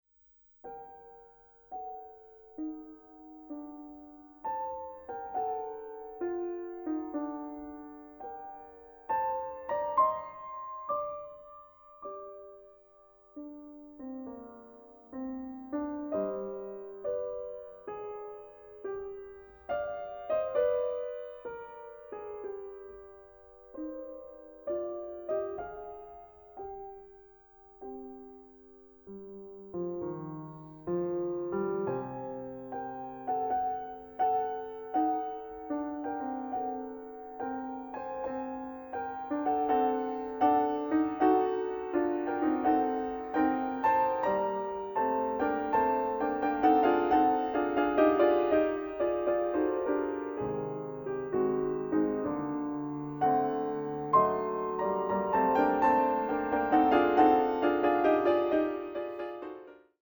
Klavierwerken aus sechs Jahrhunderten